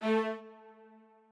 strings9_20.ogg